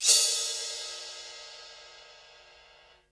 Index of /90_sSampleCDs/Roland L-CD701/CYM_FX Cymbals 1/CYM_Cymbal FX
CYM SCRAPE1.wav